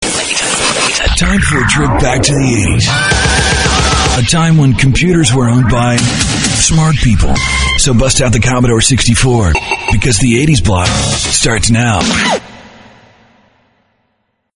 RADIO IMAGING / HOT AC